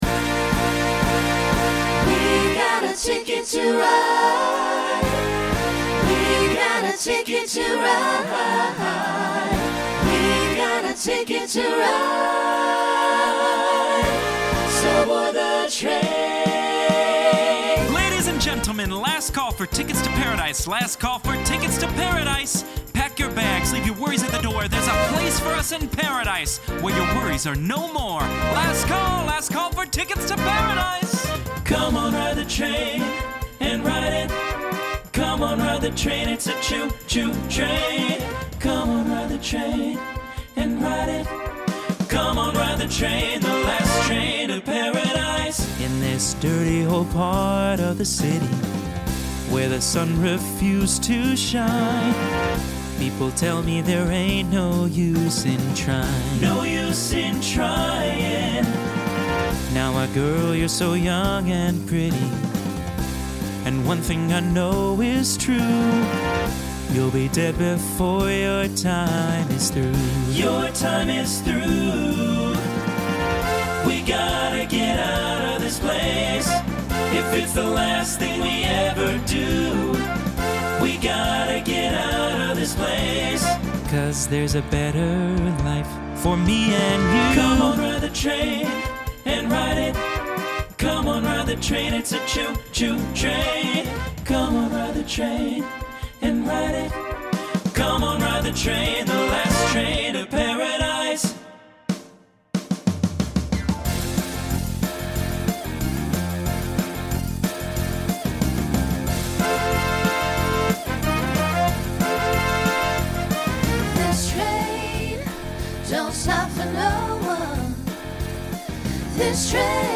Ends SATB with an overlap.
Genre Rock
Voicing Mixed